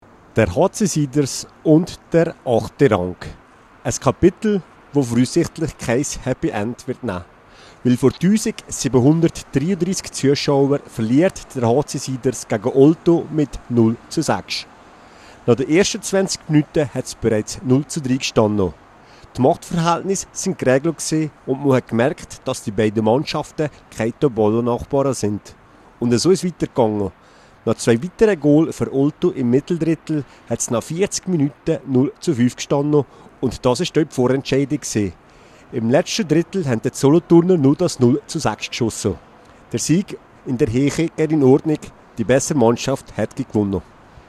0:6-Heimniederlage des HC Siders gegen Olten: Matchbericht